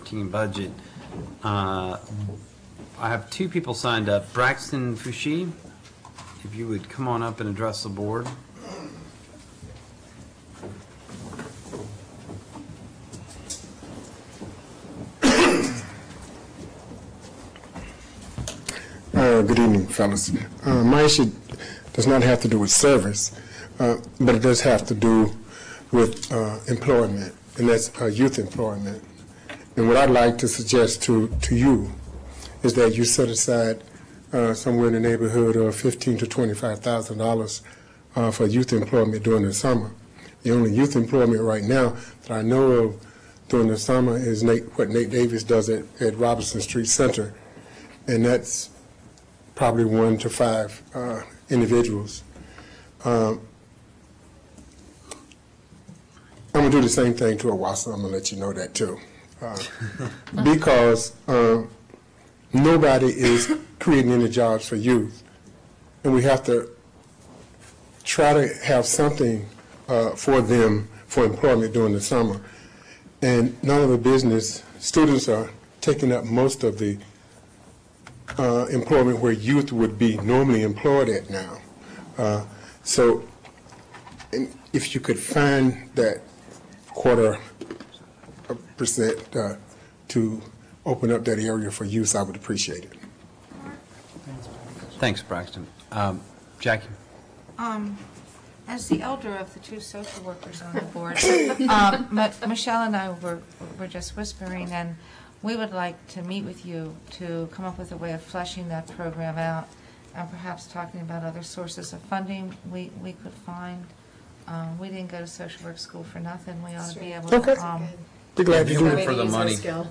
AGENDA CARRBORO BOARD OF ALDERMEN PUBLIC HEARING* Tuesday, February 28, 2012 7:30 P.M., TOWN HALL BOARD ROOM